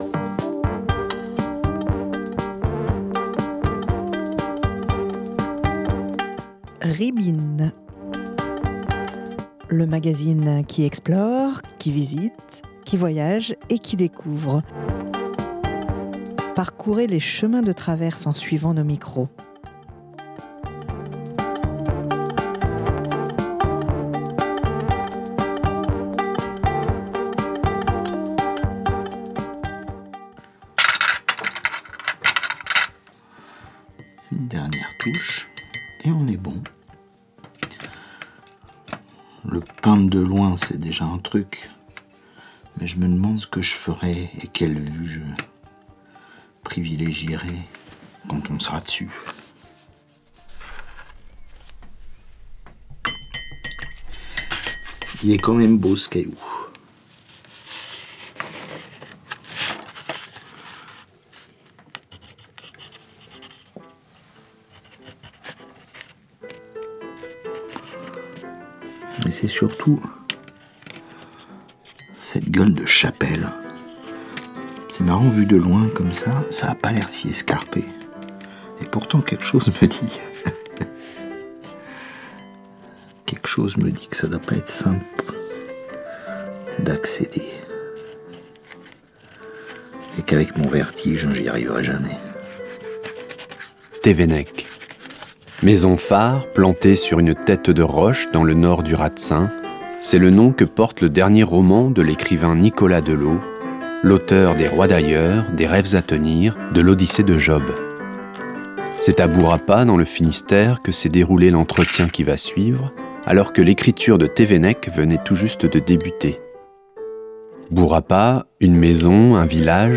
C’est à Bourapa dans le Finistère que s’est déroulé cet entretien alors que l’écriture de Tevennec venait tout juste de débuter.